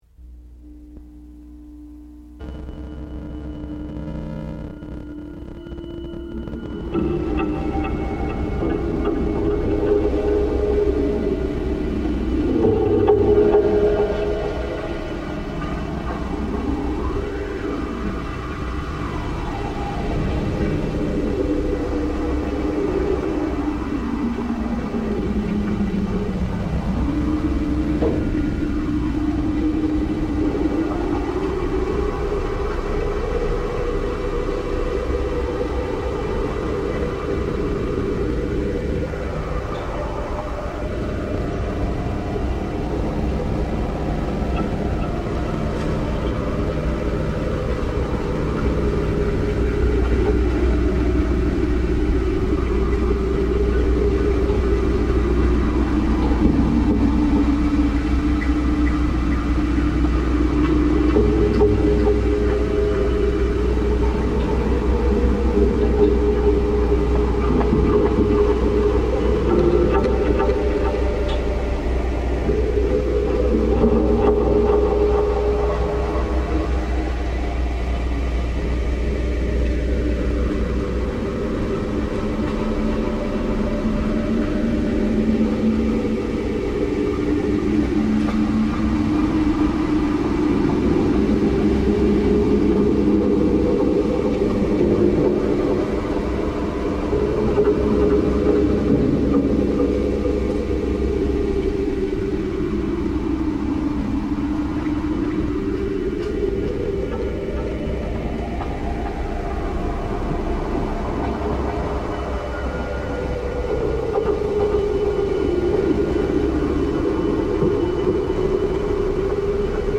Kyoto bamboo soundscape reimagined